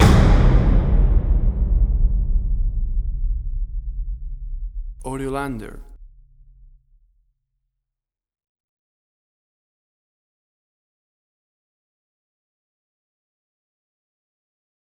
BIG DRUM HIT – 0:15″
A dark and scary Horror Hit! Perfect for Horror Trailers for hitting your cue points and making a big scary impact!
WAV Sample Rate: 16-Bit stereo, 44.1 kHz
Big-Drum-HitCS.mp3